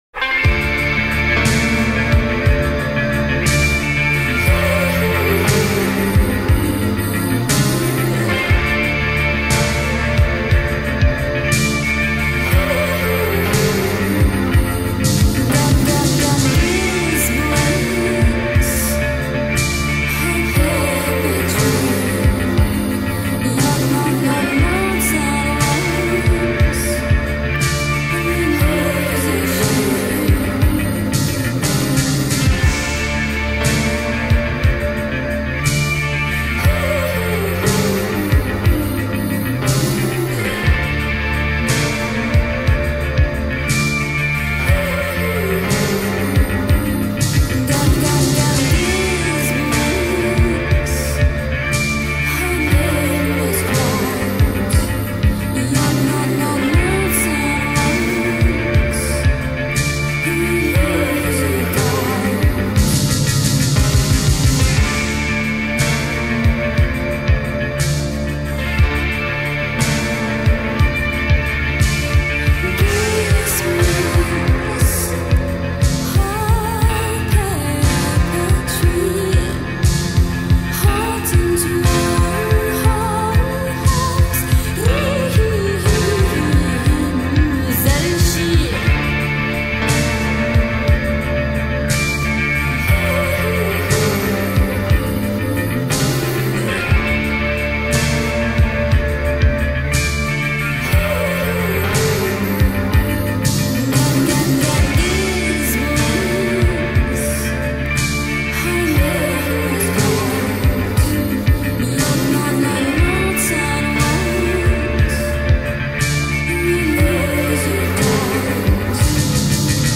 Ending up the work-week with a taste of ethereal tonight.
atmospheric and hypnotic voice
the face of haunting and ethereal .